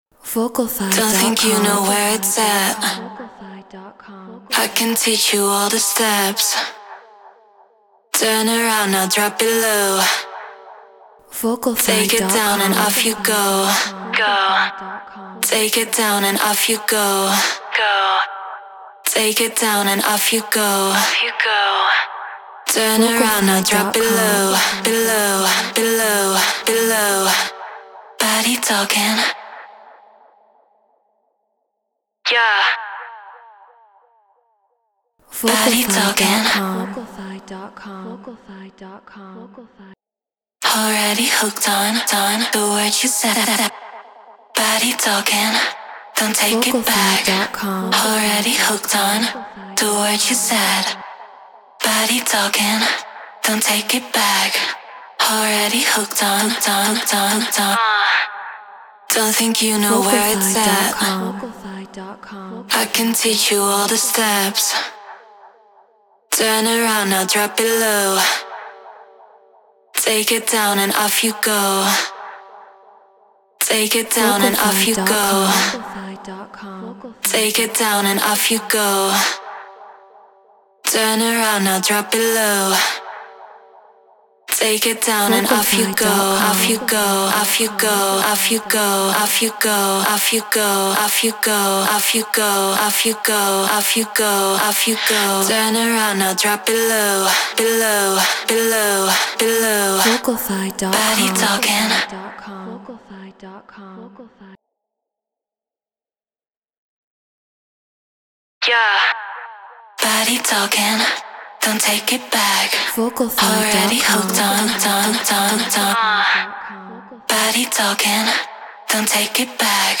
Tech House 132 BPM Fmin
Aston Spirit Apollo Twin X Logic Pro Treated Room